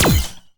weapon_laser_007.wav